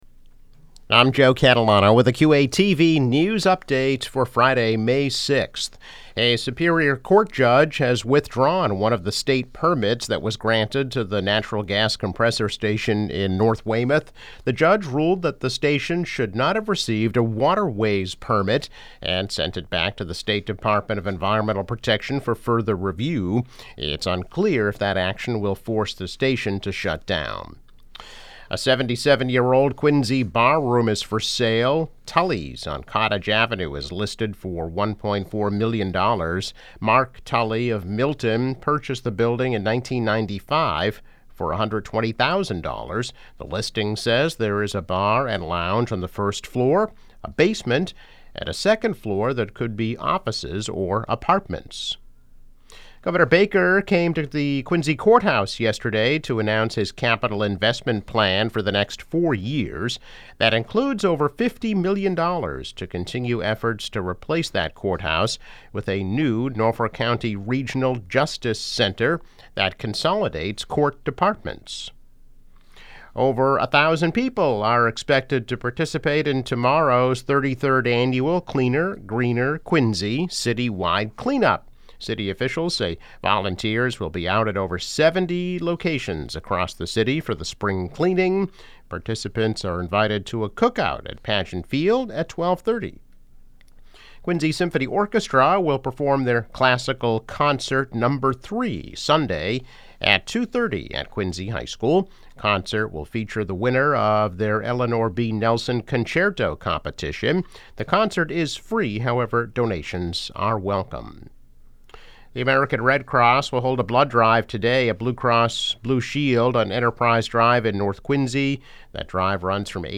News Update - May 6, 2022